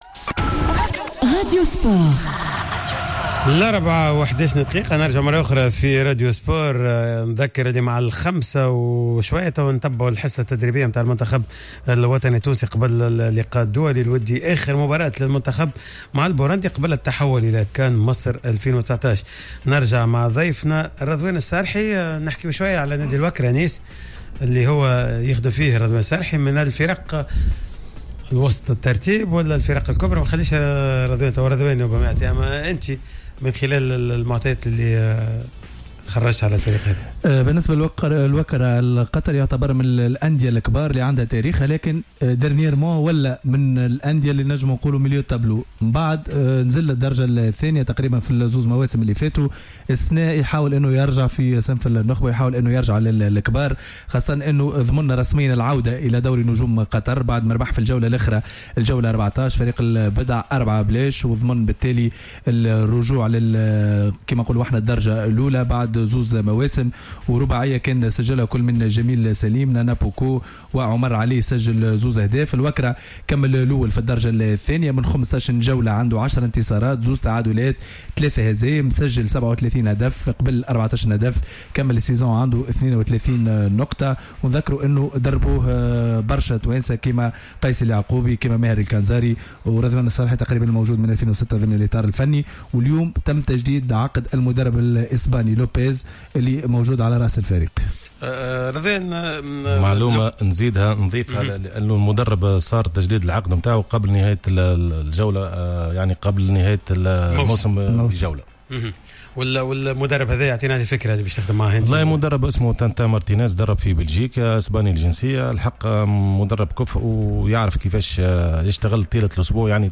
لدى حضوره في حصة "راديو سبور" أن مردود الحراس ليس في أحسن حالاته في البطولة التونسية مضيفا أن ذلك يعود إلى مشكل التكوين.